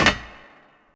MetalLandHeavy1.mp3